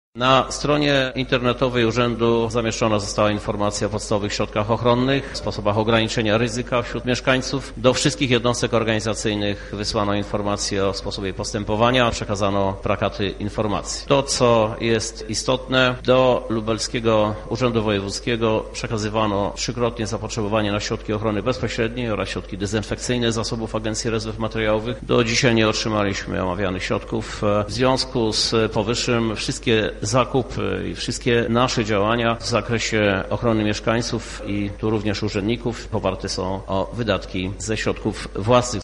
Podczas sesji Rady Miasta Krzysztof Żuk przedstawił dotychczasową działalność samorządu związaną z walką z COVID-19.
• mówi prezydent miasta Lublin Krzysztof Żuk